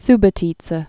(sbə-tētsə, -bô-)